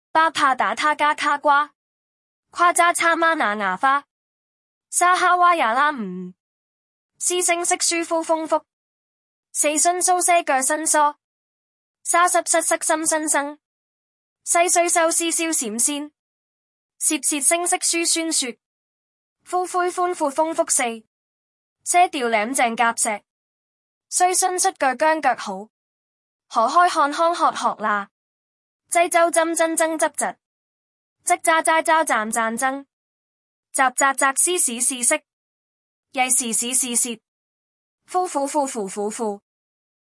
116字学会粤拼-ttsmaker-心怡.mp3